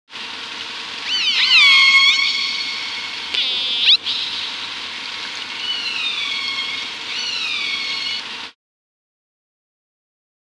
دانلود آهنگ وال 2 از افکت صوتی انسان و موجودات زنده
جلوه های صوتی
دانلود صدای وال 2 از ساعد نیوز با لینک مستقیم و کیفیت بالا